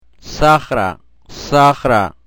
Fricatives . s